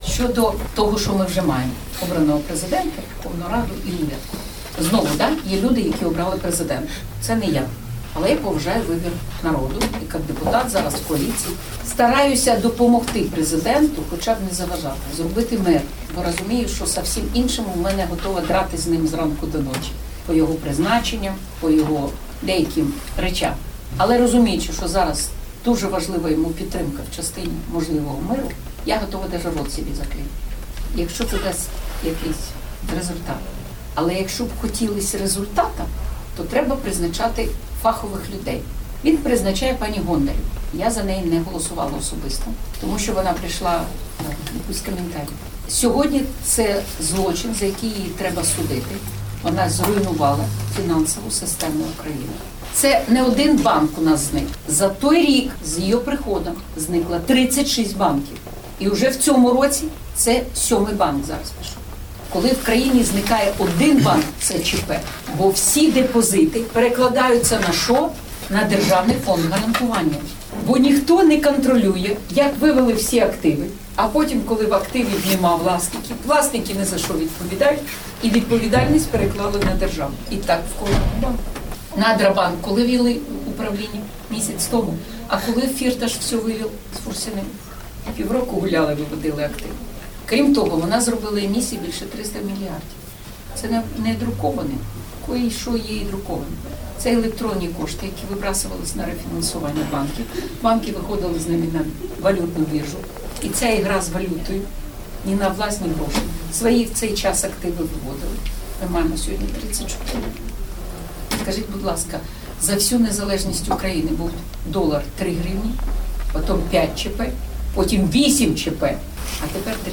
Аляксандра Кужаль, фрагмэнт інтэрвію
Пра бліжэйшыя пэрспэктывы 45-мільённай дзяржавы разважае дэпутатка Вярхоўнай Рады ад партыі «Бацькаўшчына», кандыдат эканамічных навук Аляксандра Кужаль